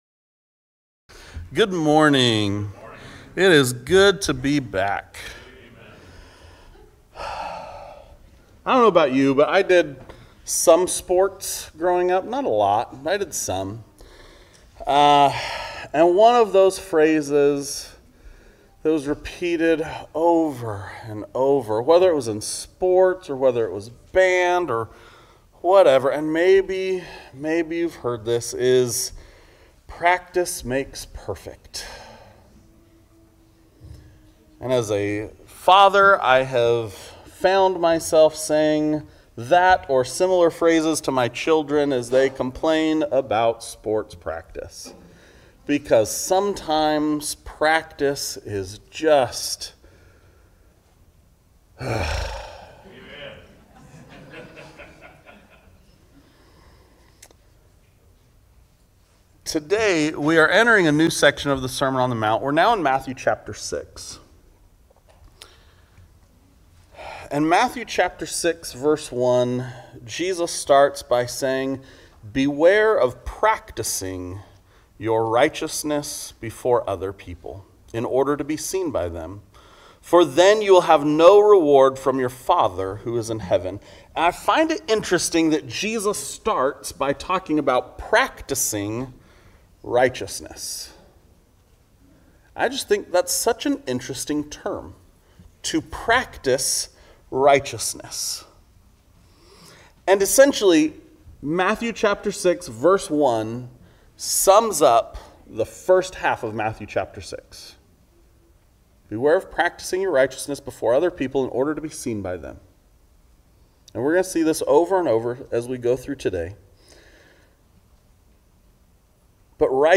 Sermons | Oregon City Church of Christ